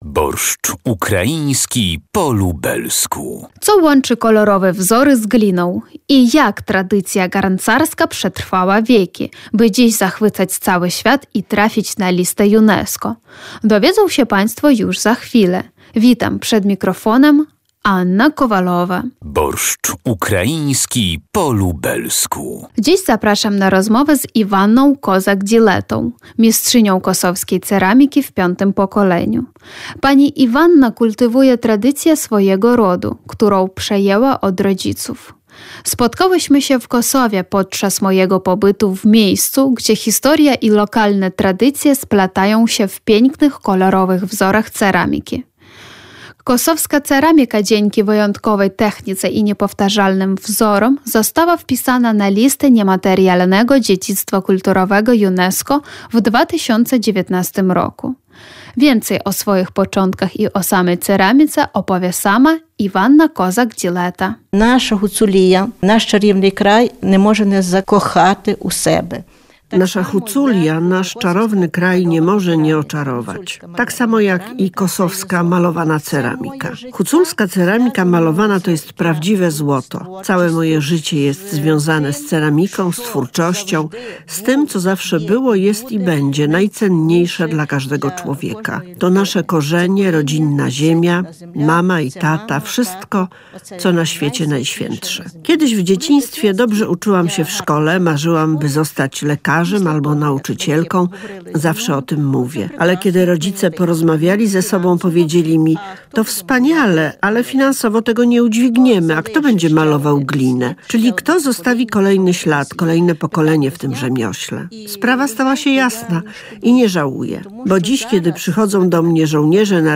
Spotkałyśmy się w Kosowie podczas mojego pobytu, w miejscu, gdzie historia i lokalne tradycje splatają się w pięknych, kolorowych wzorach ceramiki.